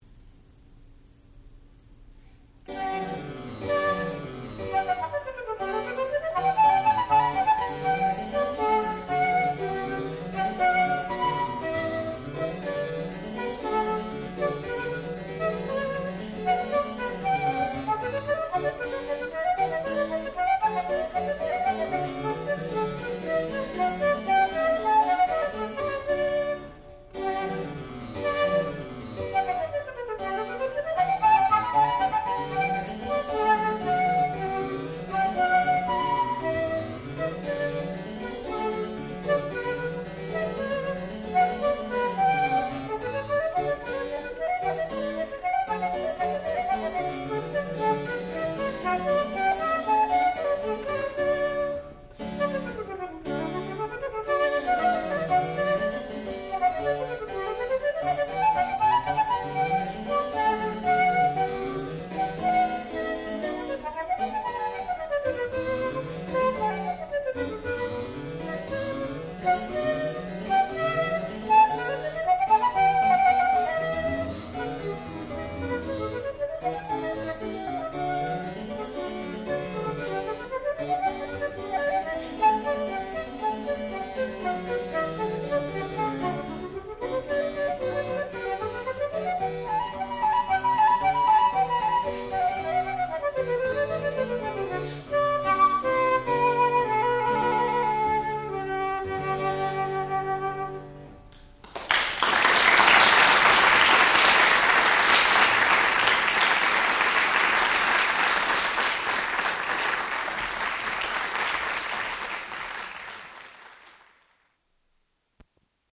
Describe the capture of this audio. Junior Recital